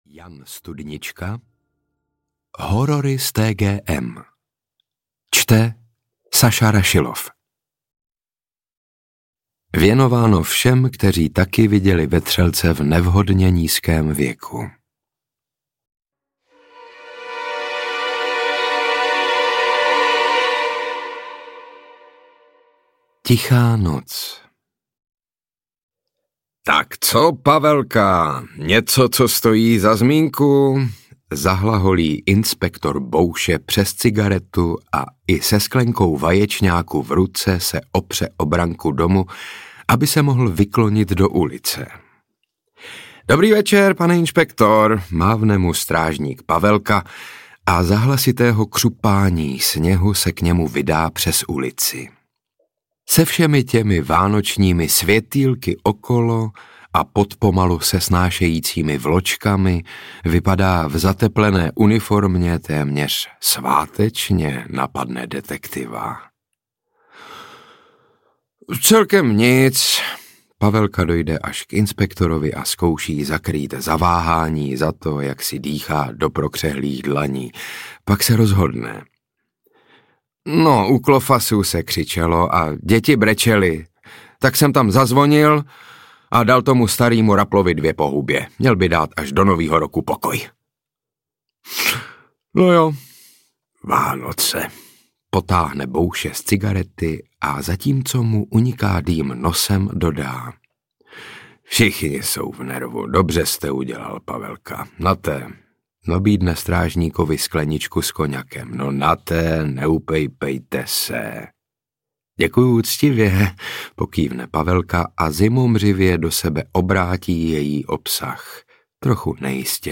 Horory s TGM audiokniha
Ukázka z knihy
• InterpretSaša Rašilov ml.